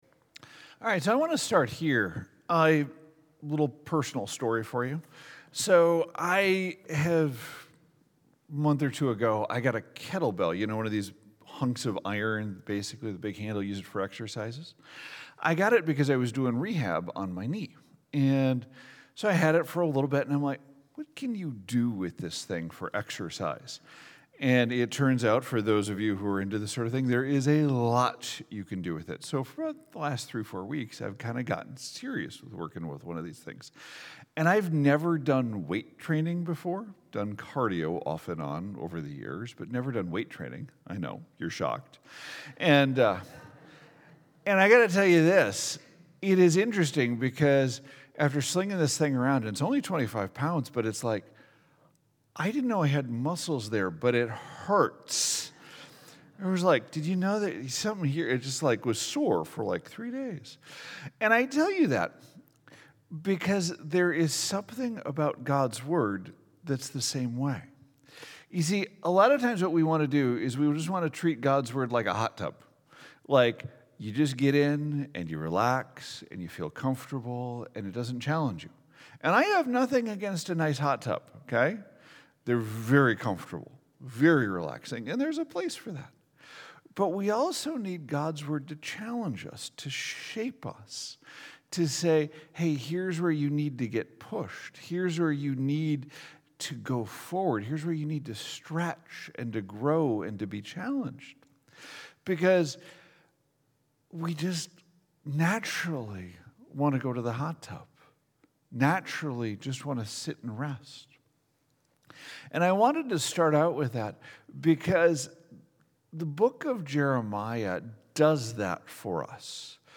Last of the sermon series: Jeremiah